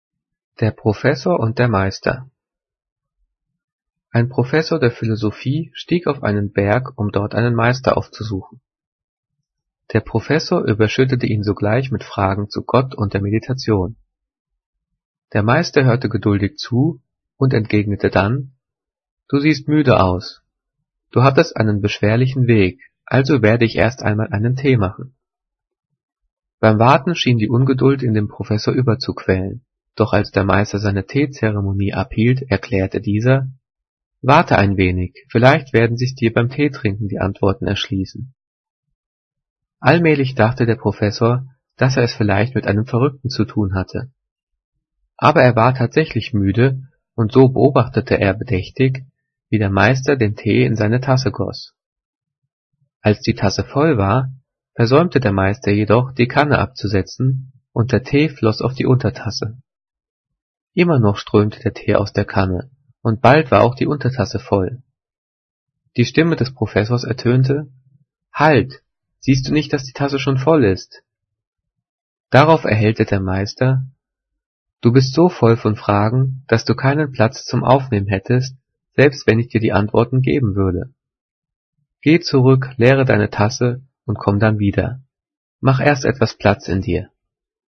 Gelesen:
gelesen-der-professor-und-der-meister.mp3